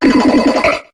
Cri de Charmina dans Pokémon HOME.